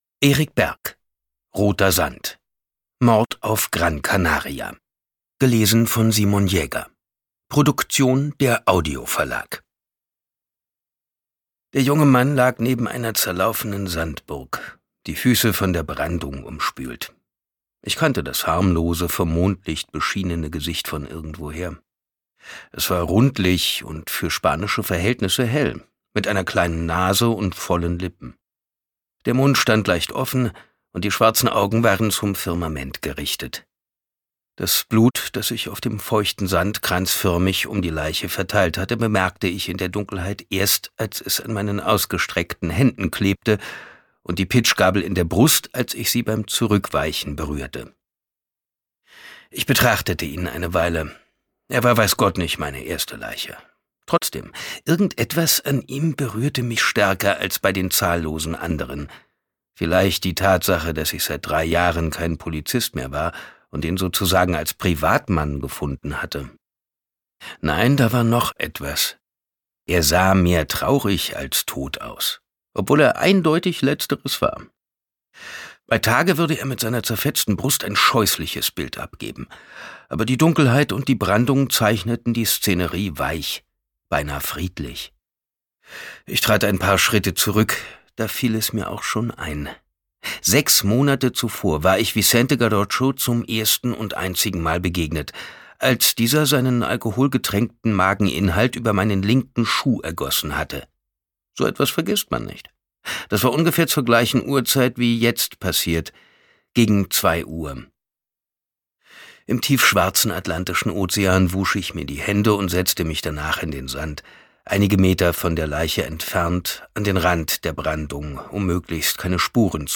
Simon Jäger (Sprecher)
Simon Jäger versteht es meisterhaft, mit seiner Stimme unterschiedliche Stimmungen zu inszenieren.